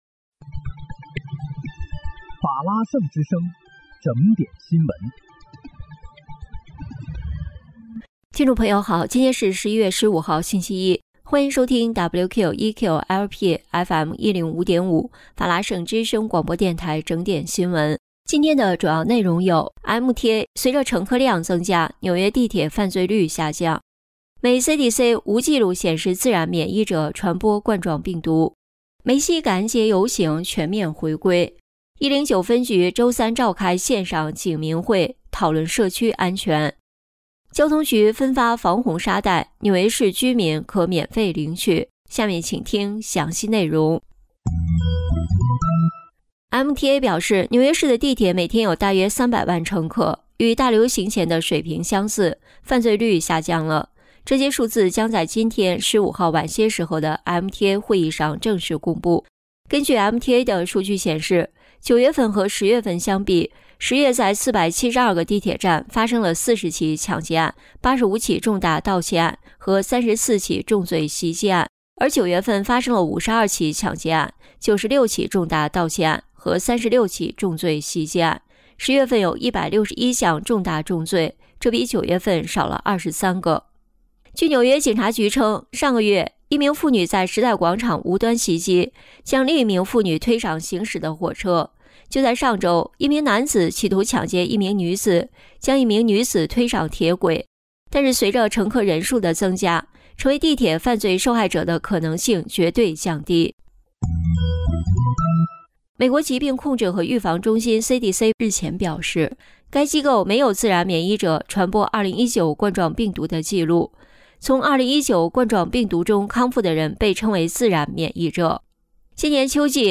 11月15日（星期一）纽约整点新闻
听众朋友您好！今天是11月15号，星期一，欢迎收听WQEQ-LP FM105.5法拉盛之声广播电台整点新闻。